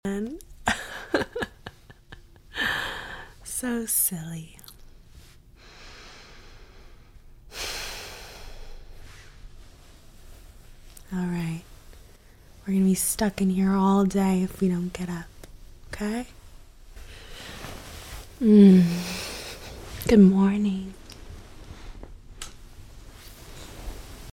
🌟 With her soft spoken words and calming ASMR triggers, you'll unwind into serenity.